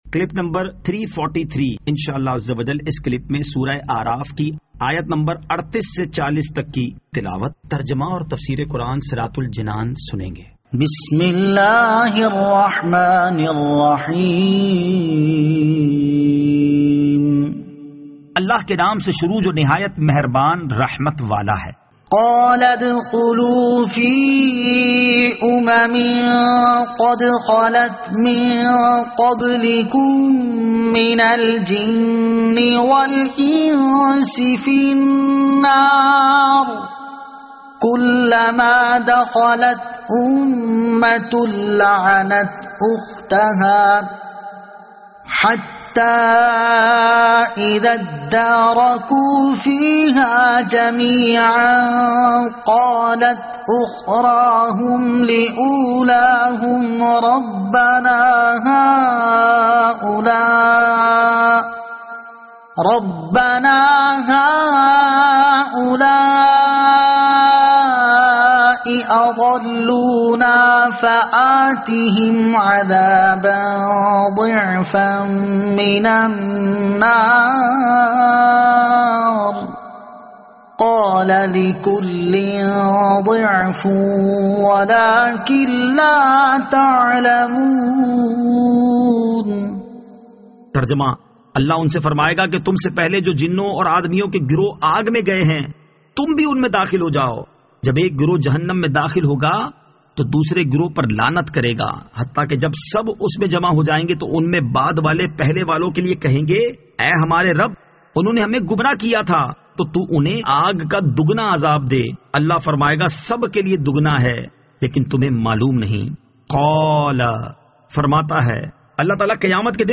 Surah Al-A'raf Ayat 38 To 40 Tilawat , Tarjama , Tafseer